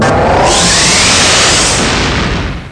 warp.wav